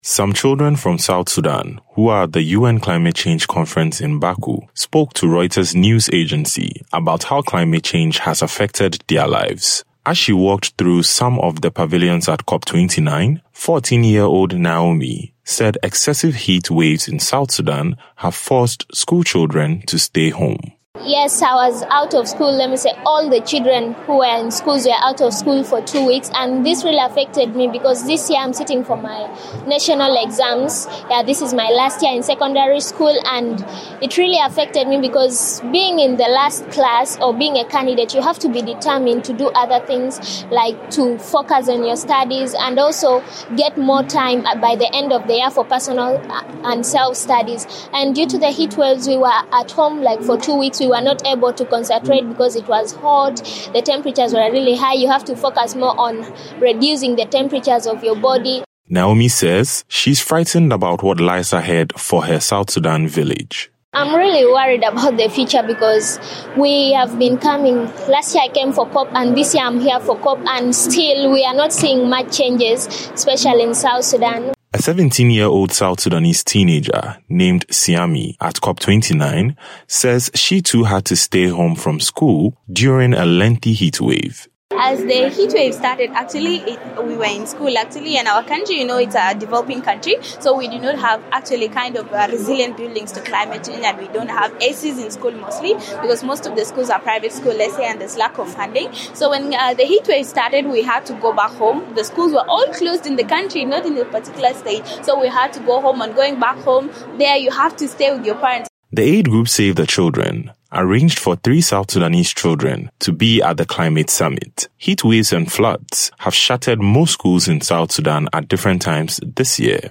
A handful of South Sudanese teens who have been funded to attend the UN climate change conference in Baku, Azerbaijan describe to Reuters how climate change has affected their lives.